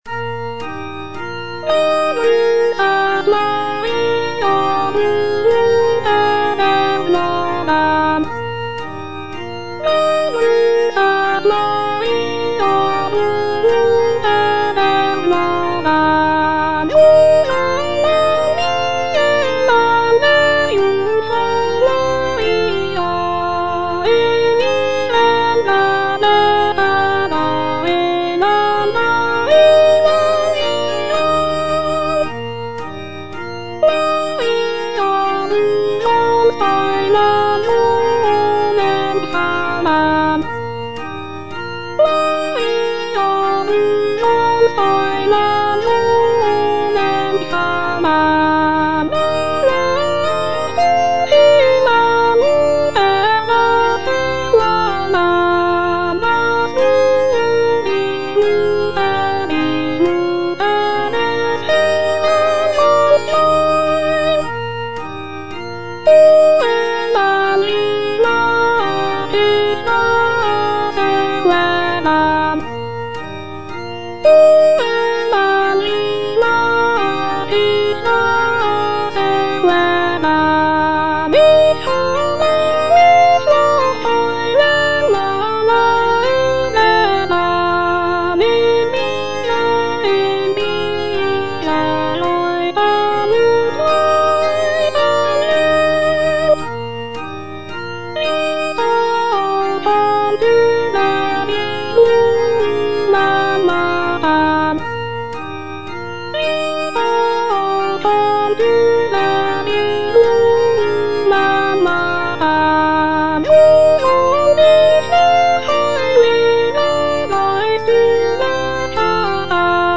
Soprano (Voice with metronome)